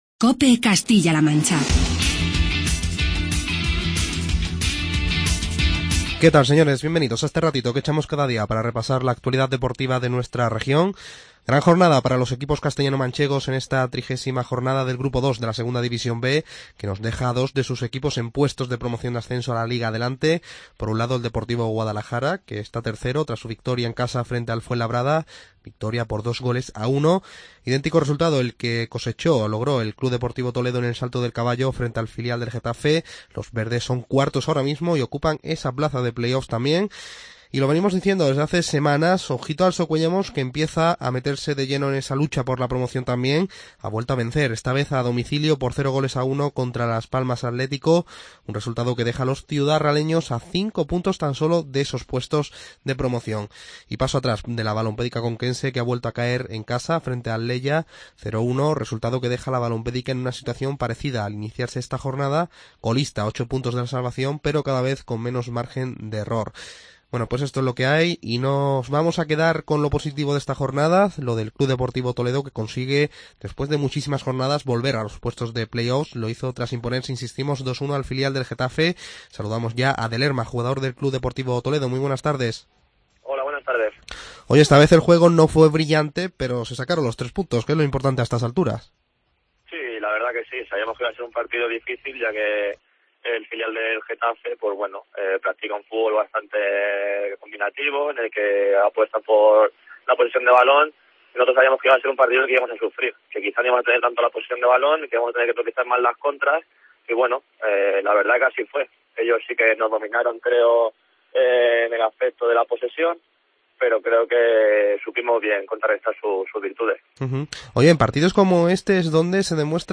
Charlamos